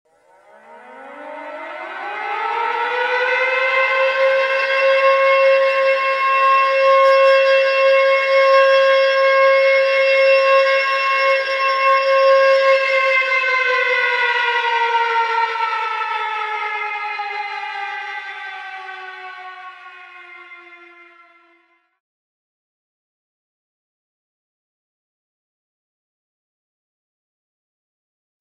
警告を知らせる警報音。
試聴とダウンロード 警告を知らせる警報音。エアーサイレン風の音。